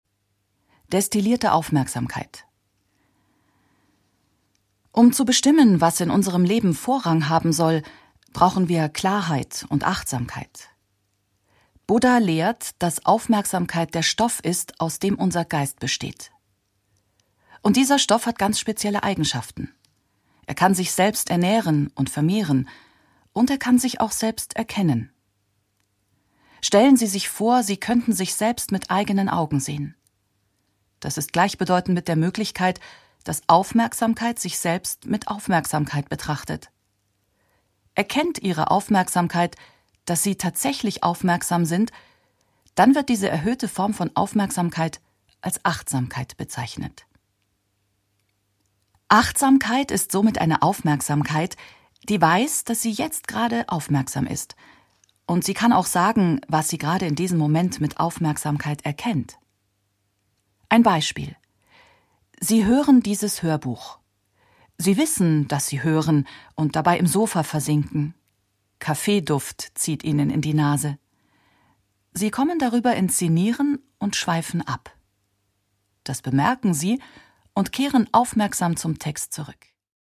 Produkttyp: Hörbuch-Download
Fassung: Autorisierte Lesefassung